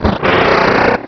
pokeemerald / sound / direct_sound_samples / cries / sharpedo.aif
sharpedo.aif